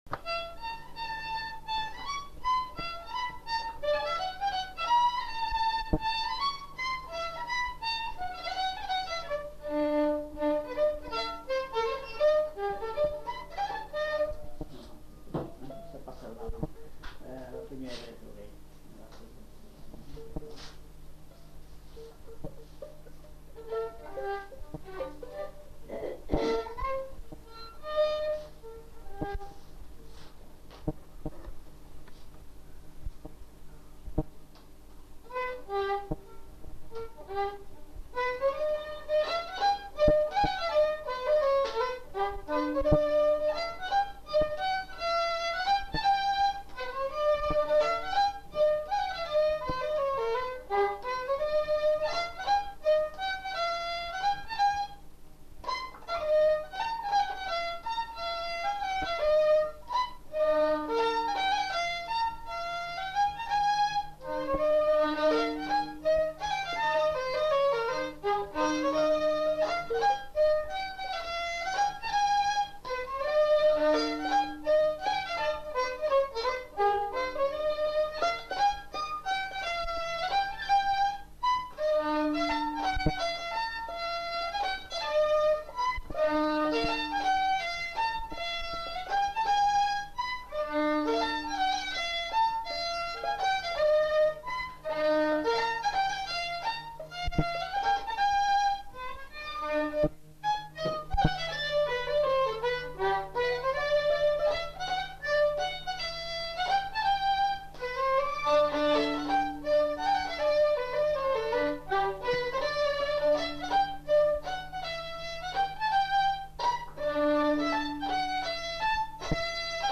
Lieu : Saint-Michel-de-Castelnau
Genre : morceau instrumental
Instrument de musique : violon
Danse : mazurka
Notes consultables : Recherche d'un air en fin de séquence.